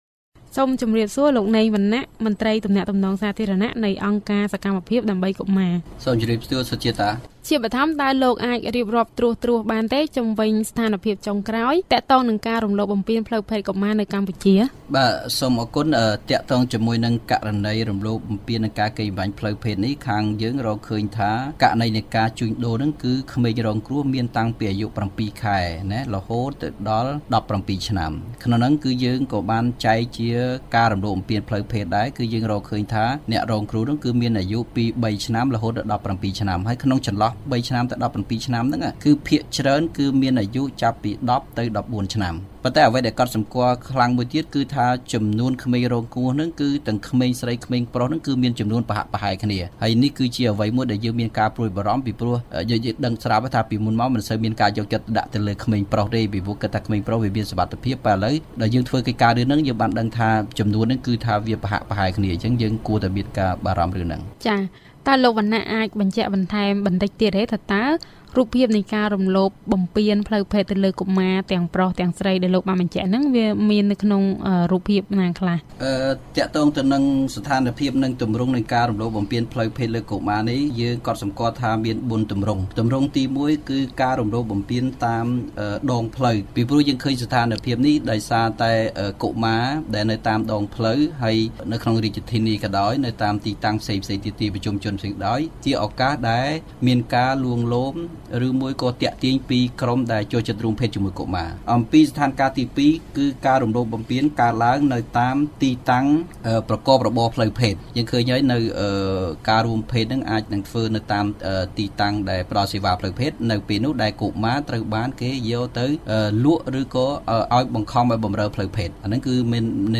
បទសម្ភាសន៍ VOA៖ ការរំលោភផ្លូវភេទលើកុមារា និងកុមារីមានចំនួនប្រហាក់ប្រហែលគ្នាក្នុងឆ្នាំ២០១៦